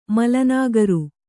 ♪ mala nāgaru